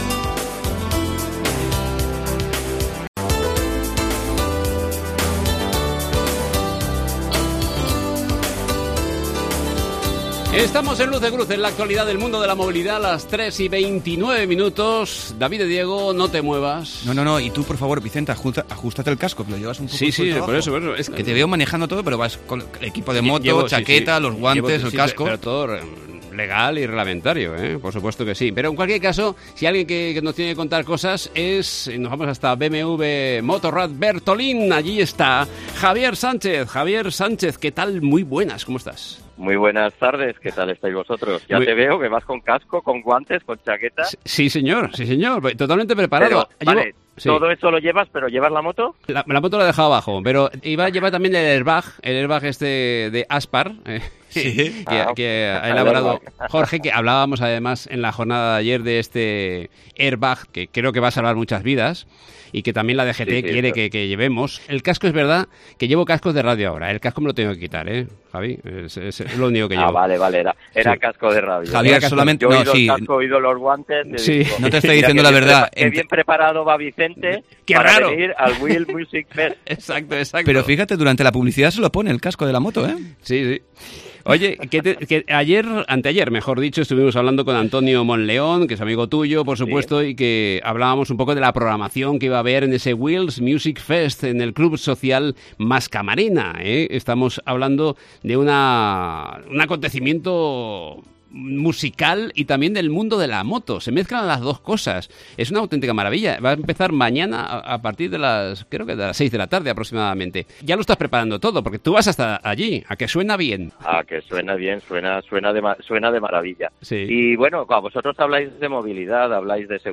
Así sonará, este fin de semana, una moto de BMW MOTORRAD BERTOLÍN